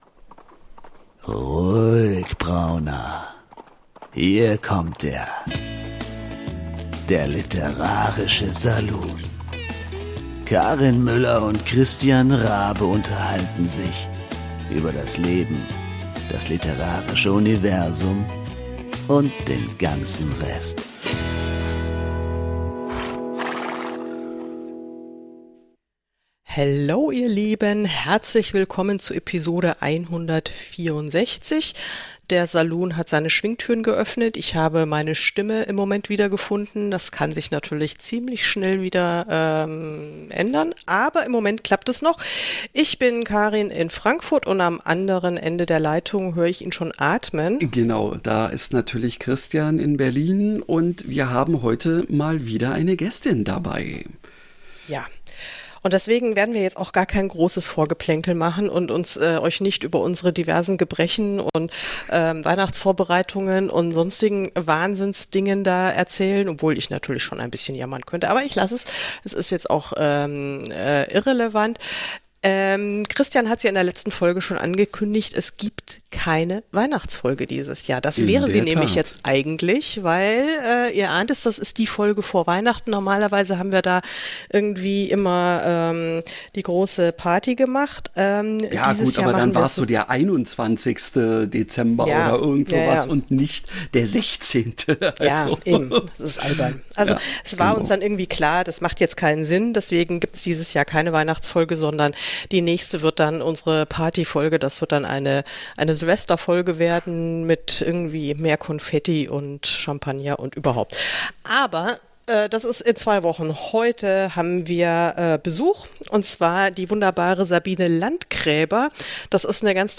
Es kamen diesmal ausschließlich Kolleginnen zu Wort, die 2023 auch im Saloon zu Gast waren. Einige der Beiträge sind vielleicht nicht ganz so fluffig und leicht verdaulich wie man zu Weihnachten annehmen könnte.